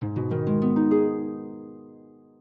soundblocks_harp3.ogg